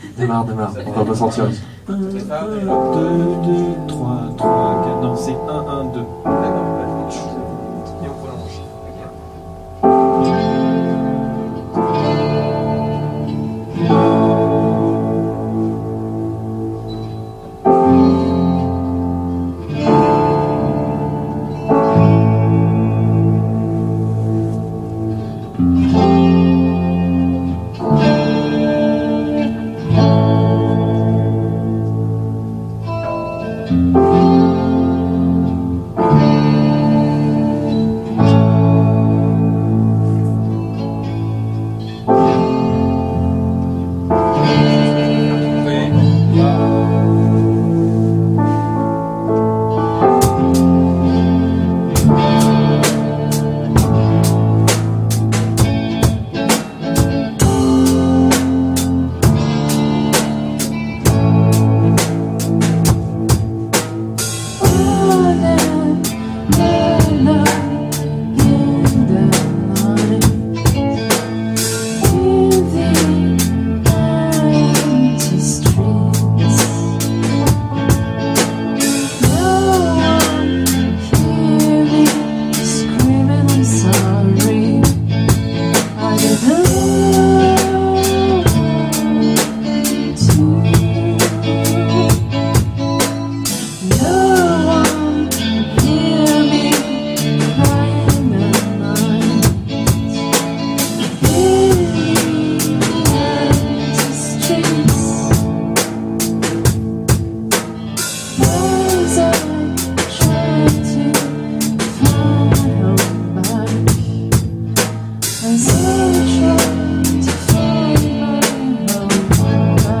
avec debrief